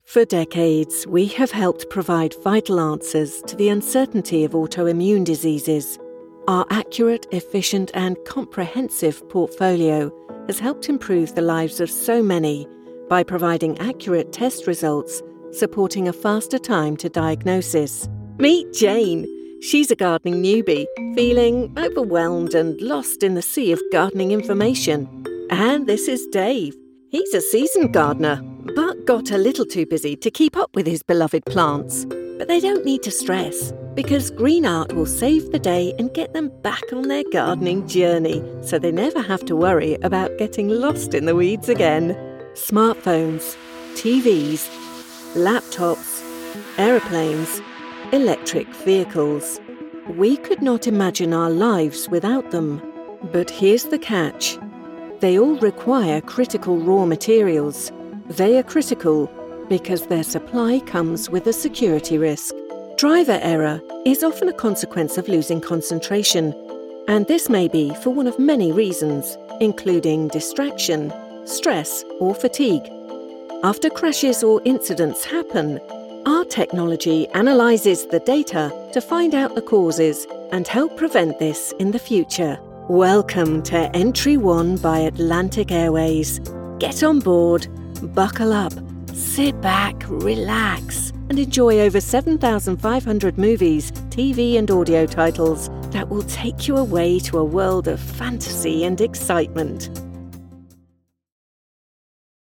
Maduro, Comercial, Versátil, Cálida, Empresarial
Corporativo
Su voz es descrita como confiable, juguetona, inteligente y cercana.